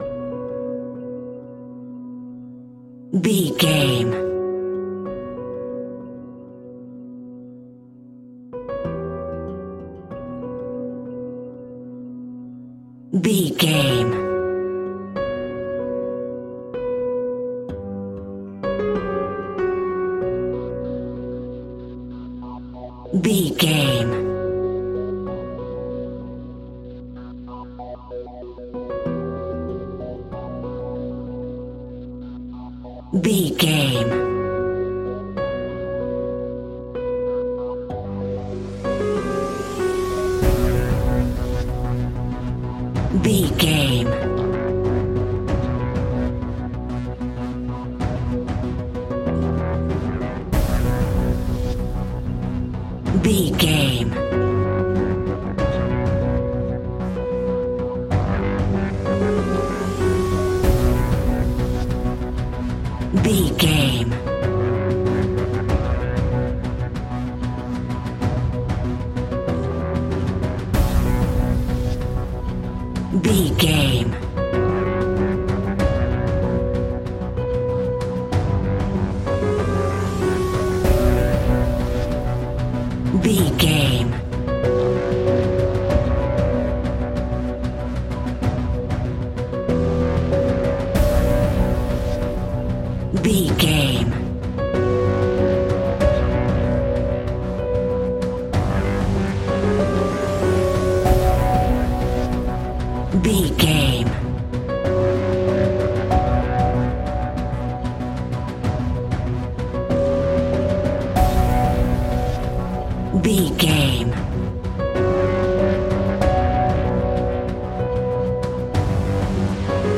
Aeolian/Minor
tension
ominous
eerie
drums
percussion
synthesiser
Horror Pads
horror piano